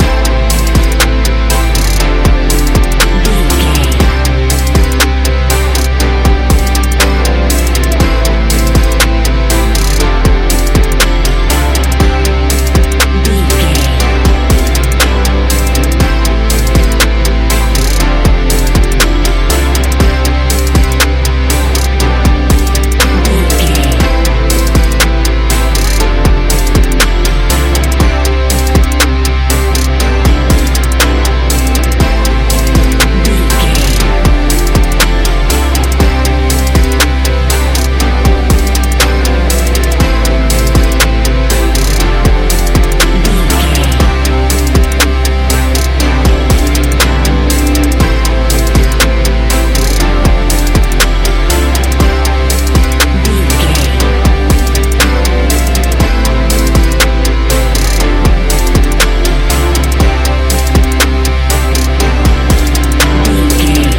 Ionian/Major
ambient
chill out
downtempo
pads